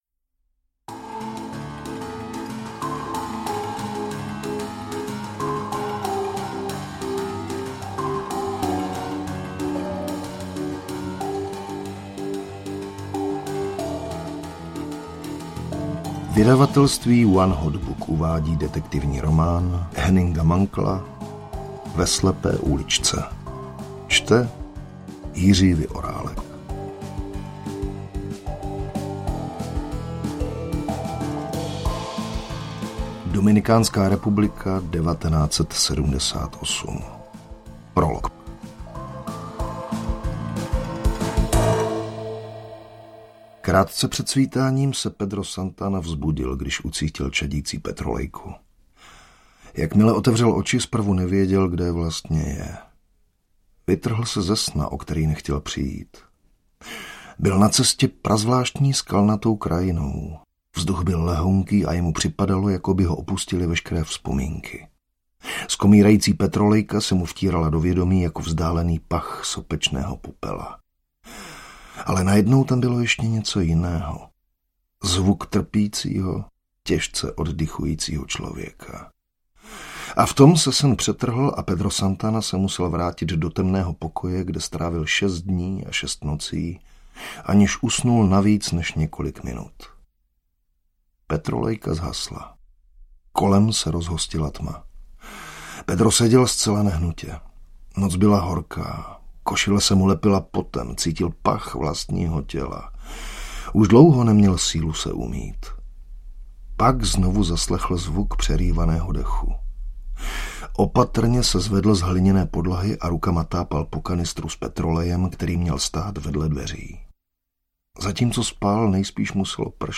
Ve slepé uličce audiokniha
Ukázka z knihy
• InterpretJiří Vyorálek